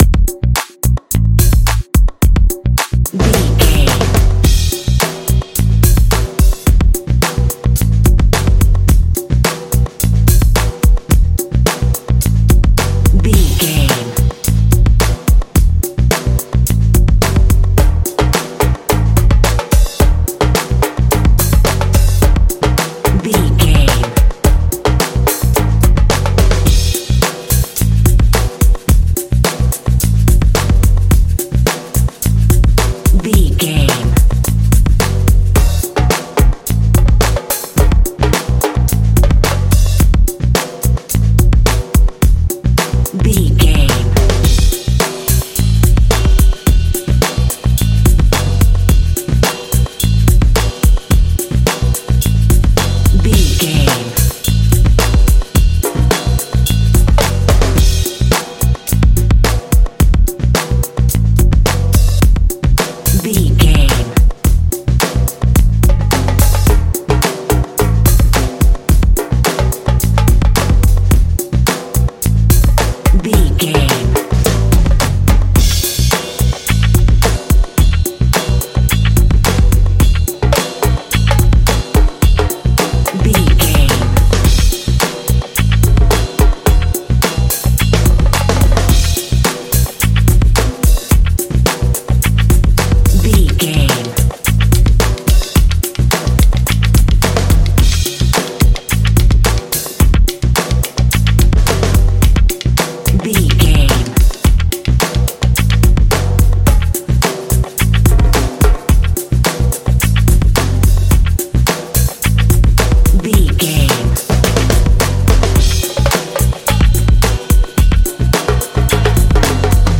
Ionian/Major
groovy
cheerful/happy
bass guitar
drums
percussion
contemporary underscore